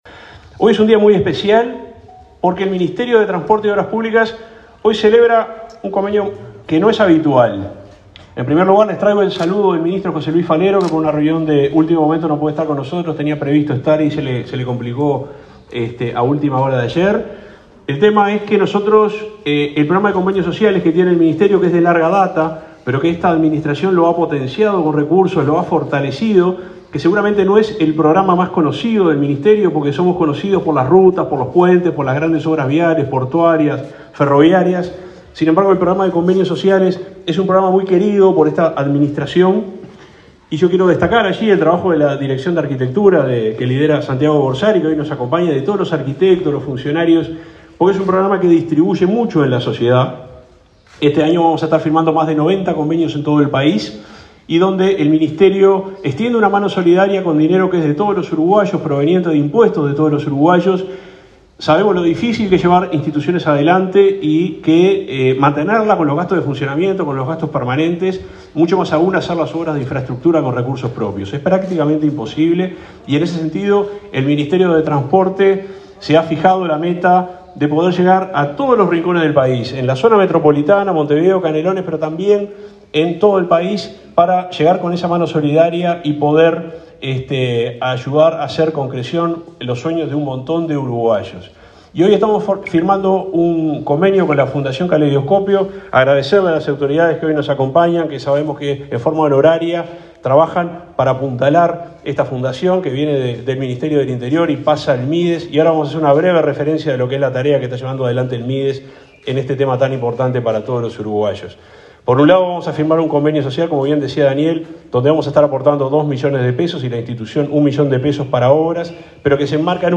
Palabra de autoridades en acto del MTOP y el Mides